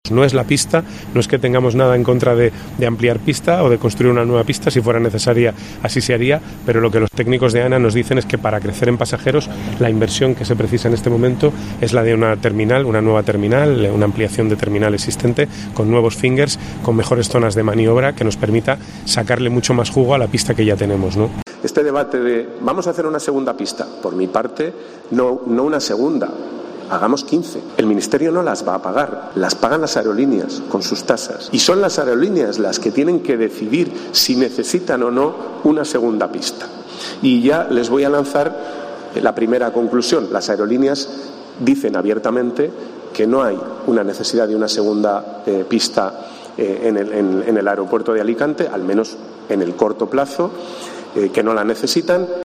En una conferencia organizada en Casa Mediterráneo por el Foro Alicante delante la ministra de Ciencia y líder del PSPV-PSOE, Diana Morant, y decenas de representantes empresariales e institucionales de la provincia y la Comunitat Valenciana, el ministro ha explicado que las nuevas pistas de vuelo "no las paga" el Ministerio "sino las aerolíneas" a través de las tasas aeroportuarias.